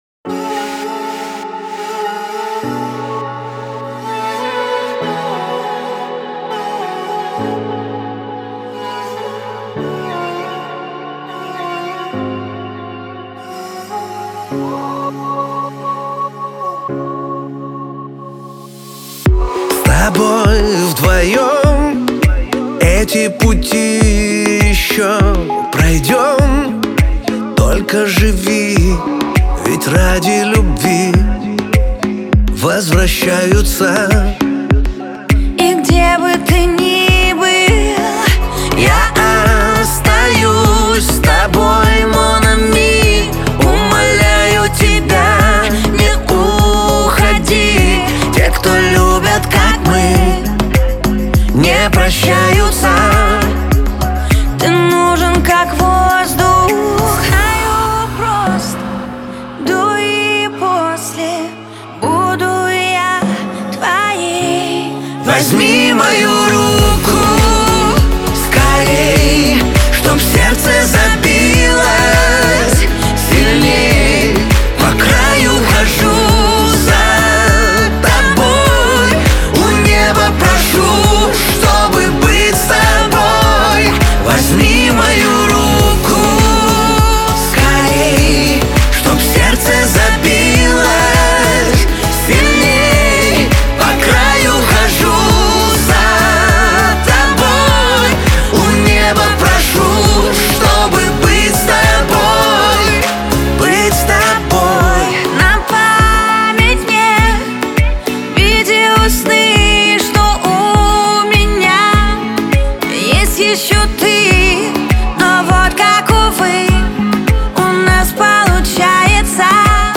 это яркая и эмоциональная композиция в жанре поп